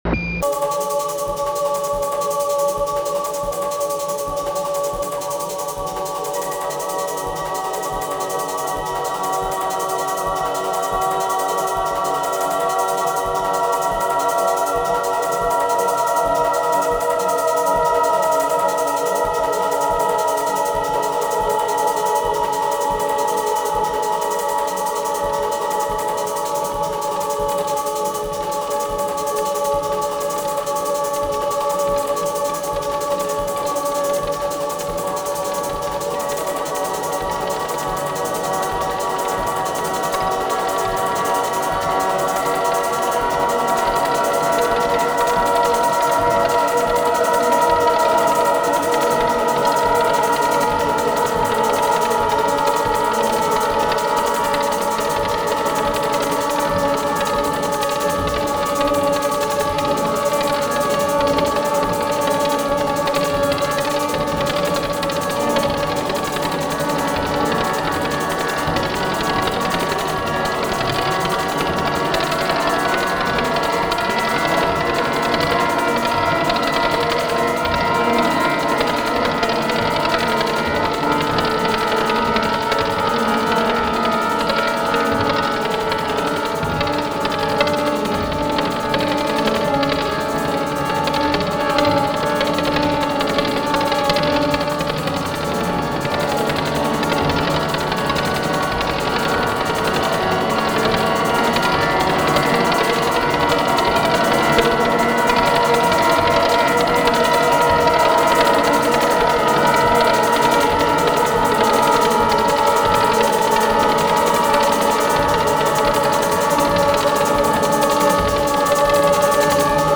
合唱、
音声モーフィング、
オルガン、
ハードコア・ビート、
不整動パンニング、
非実存ギターによるパーカッシブ・タッピング、